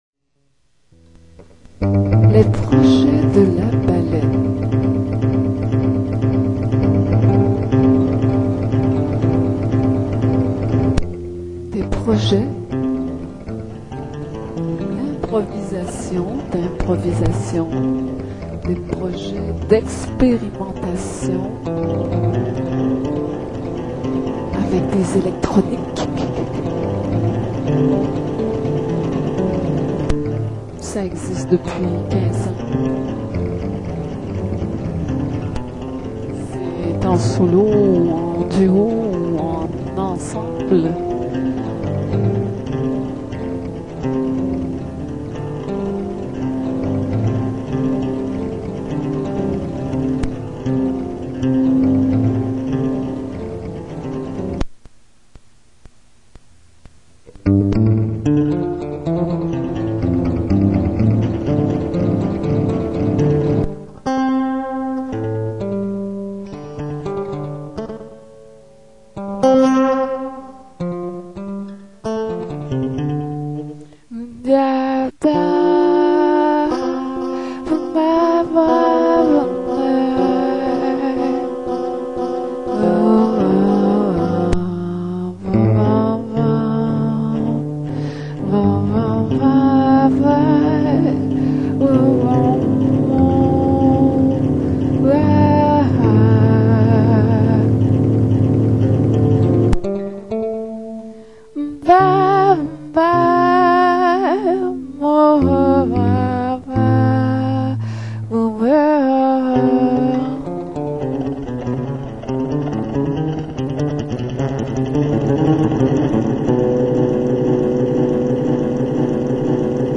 Atelier 2006 Musique improvisée et poésie à l'honneur
guitare, électroniques,multimédia, séquenseur midi, voix, textes